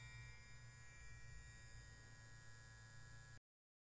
剃刀 " 剃刀 剃刀
描述：电动剃须机。
Tag: 剃须刀 机械 机器 电动剃刀 剃须机 电动剃须刀 发动机